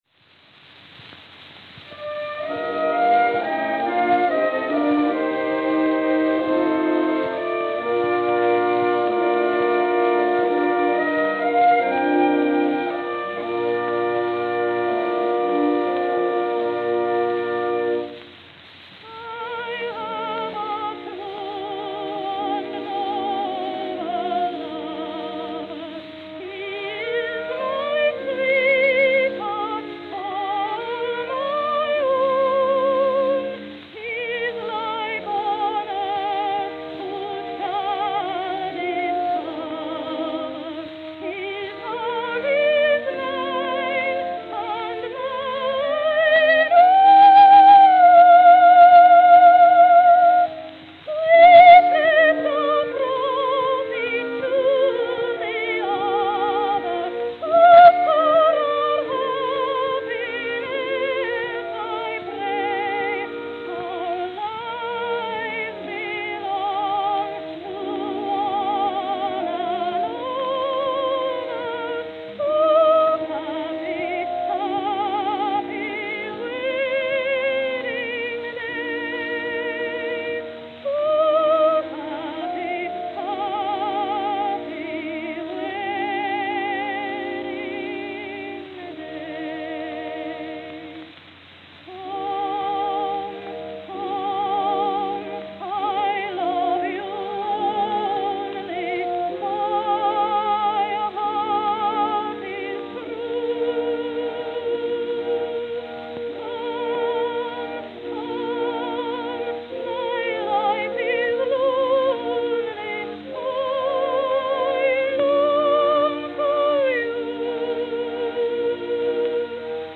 Camden, New Jersey (?)